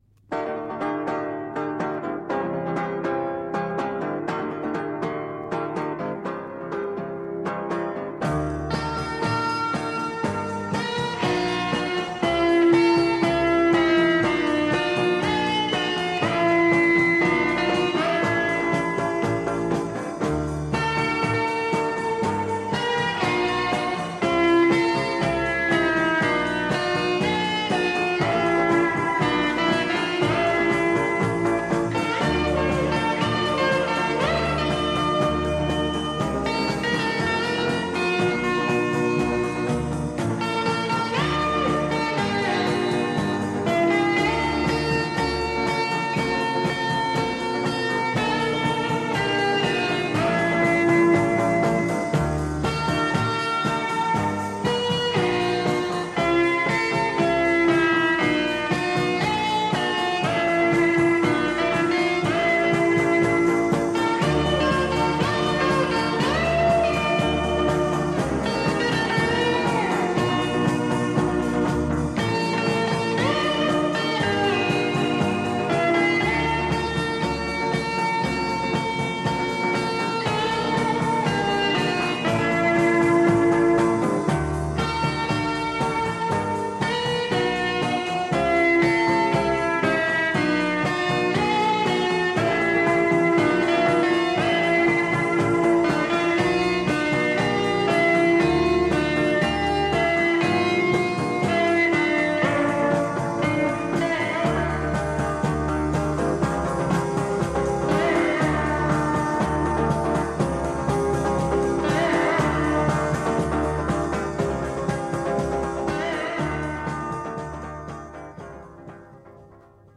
radiomarelamaddalena / STRUMENTALE / GUITAR HAWAY / 1 /